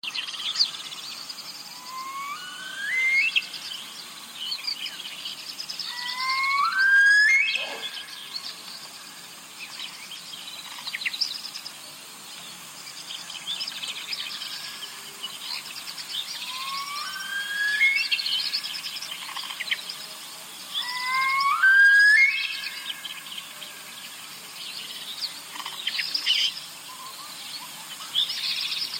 کبک دری؛ بزرگ‌ترین کبک ایران با پر‌های خاکستری که نماد حیات وحش چهارمحال و بختیاری‌ست و آواز وهم‌آلودی دارد